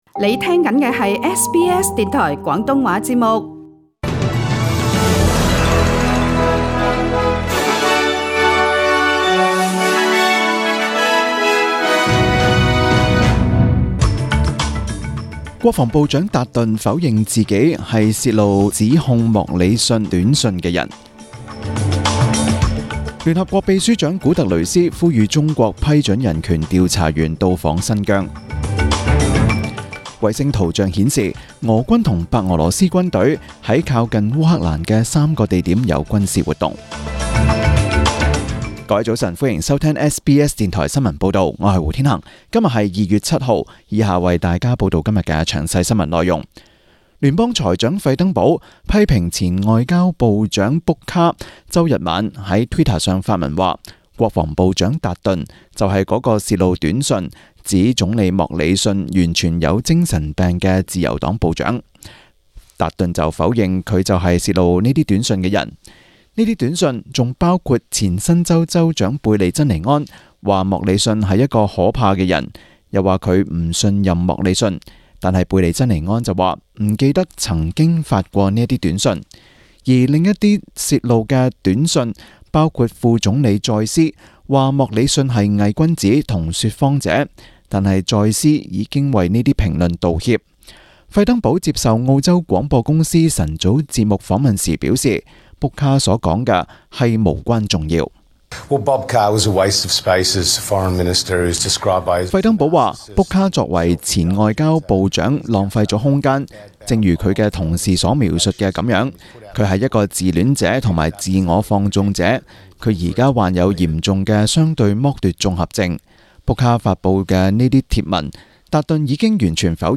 中文新聞 （2月7日）